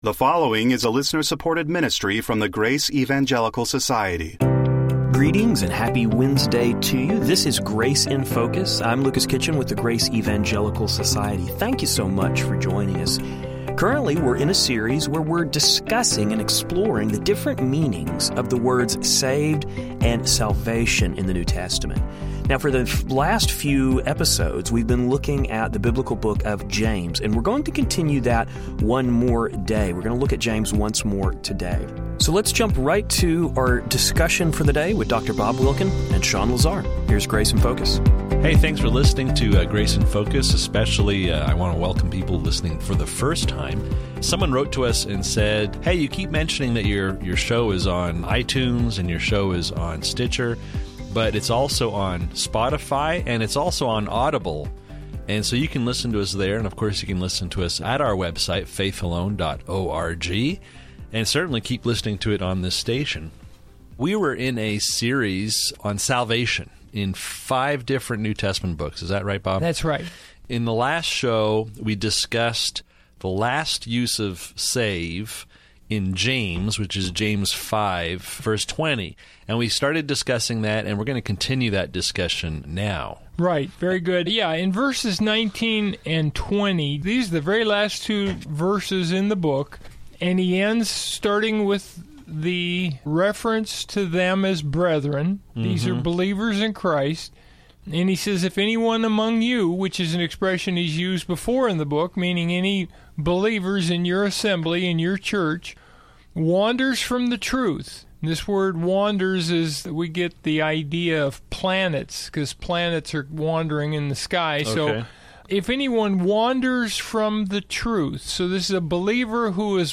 On todays episode, we will hear the guys address the final example of the term “save” in the book of James, found in 5:20. In addition to the term “save” we also see James use the term “soul” in this passage.